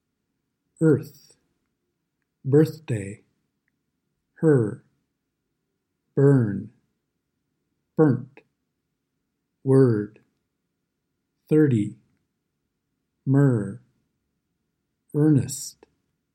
Lesson 5 – “R” vowels /ɝ/, /ɚ/, /ɑr/, /ɜr/, /ɪr/, /ɔr/ – American English Pronunciation
The /ɝ/ sound